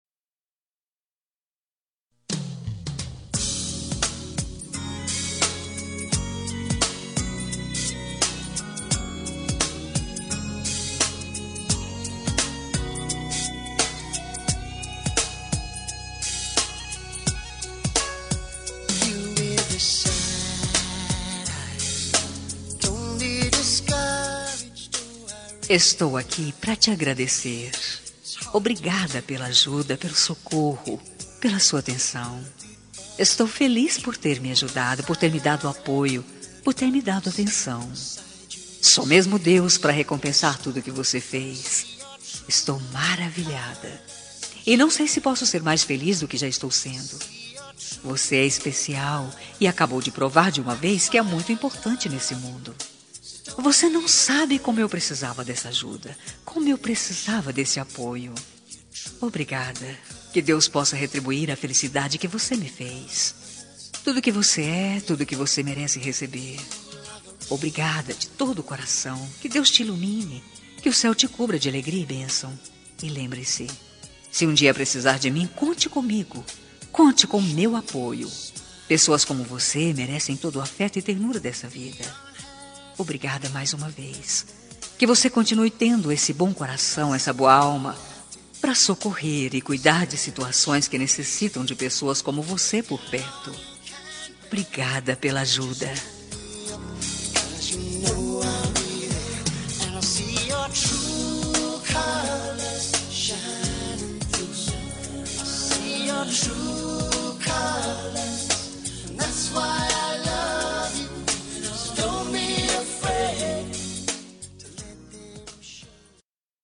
Telemensagem de Agradecimento – Pela Ajuda – Voz Feminina – Cód: 23
Obrigado pela ajuda- fem- neutra -2037.mp3